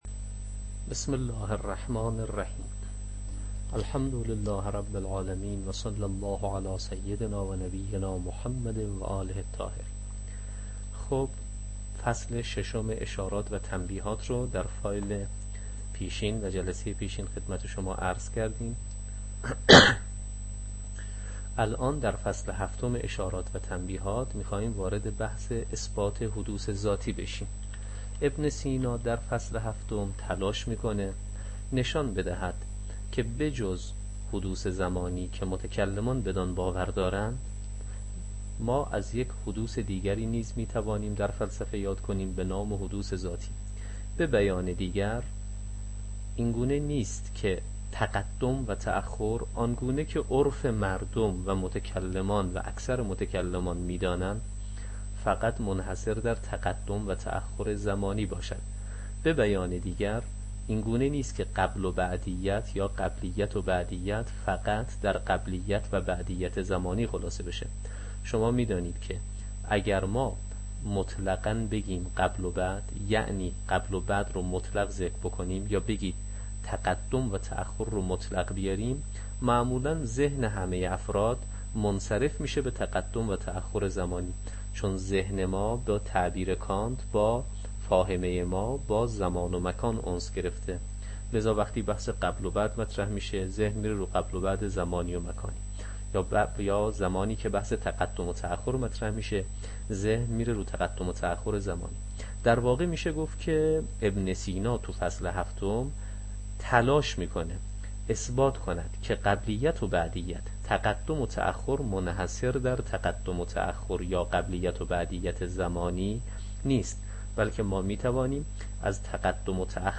شرح اشارات و تنبیهات، تدریس